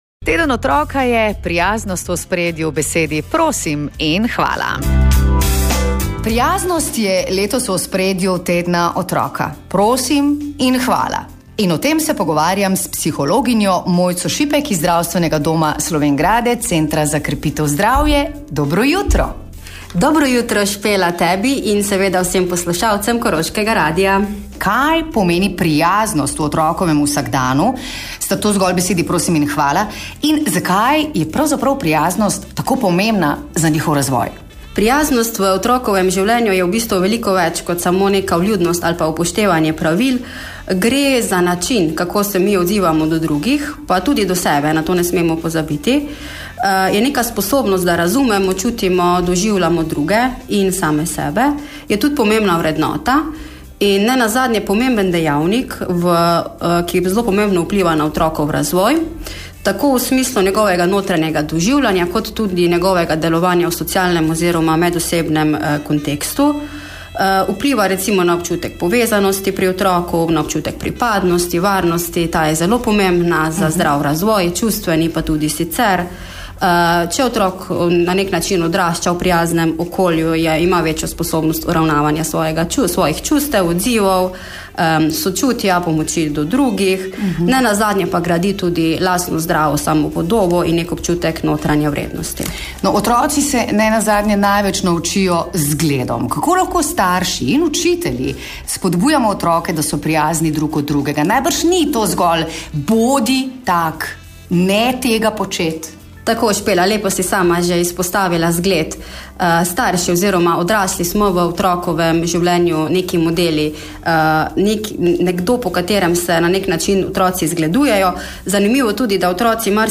Več v pogovoru.